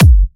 VFH2 128BPM Unimatrix Kick.wav